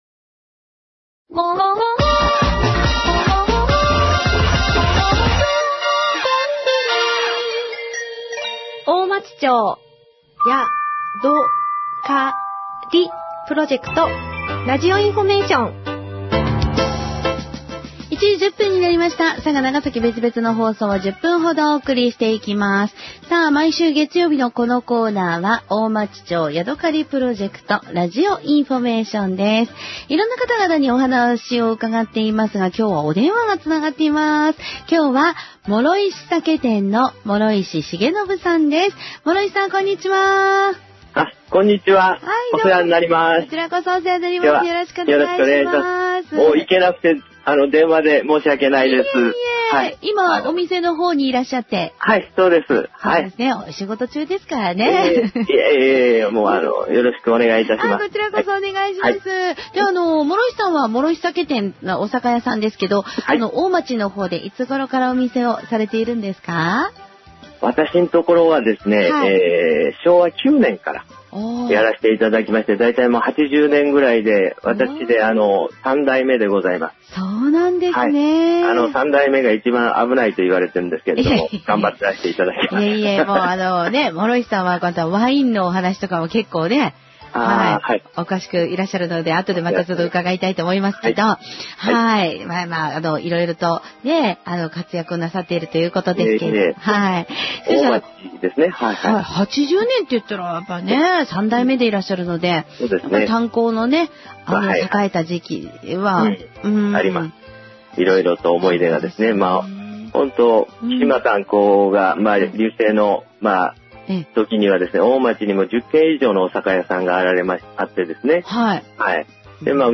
このページでは、平成27年1月26日放送から平成27年6月29日の期間、NBCラジオ佐賀で放送された「大町町やどかりプロジェクトラジオインフォメーション」の番組内容をご紹介します。